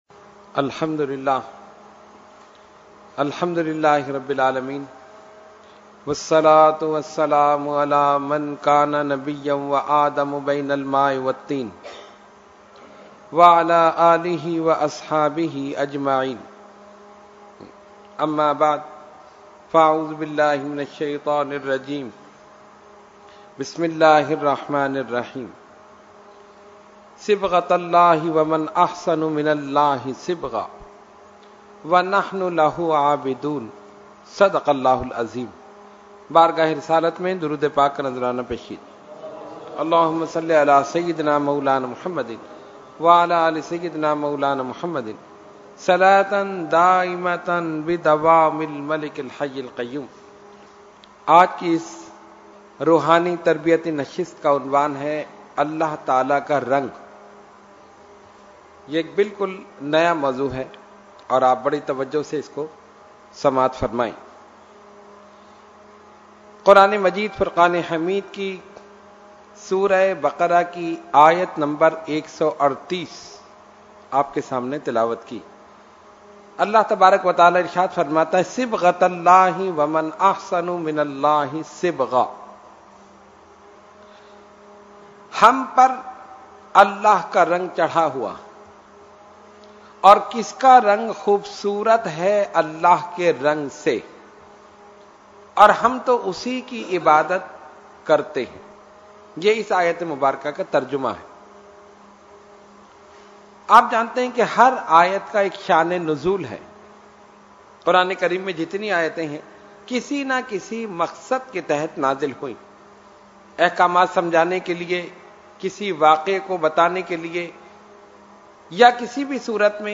Category : Speech | Language : UrduEvent : Weekly Tarbiyati Nashist